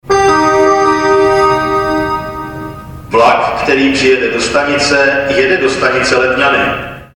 - Staniční hlášení o příjezdu soupravy směr Letňany si